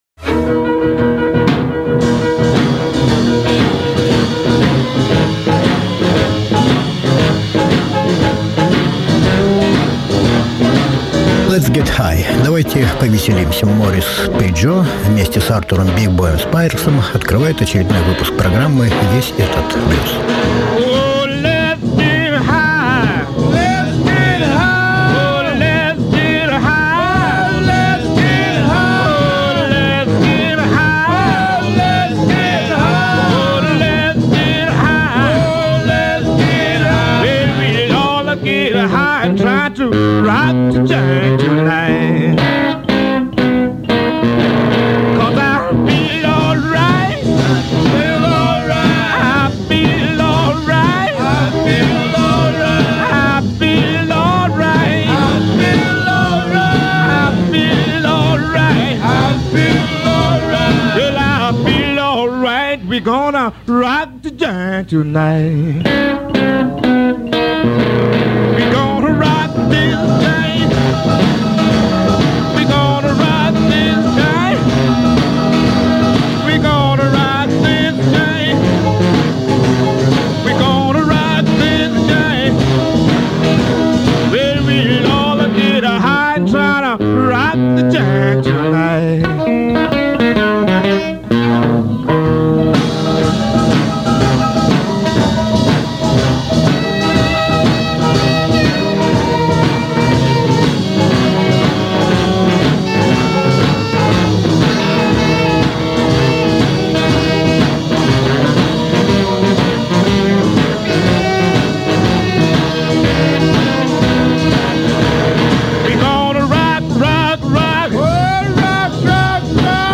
И вновь в программе блюзовые юбилеи.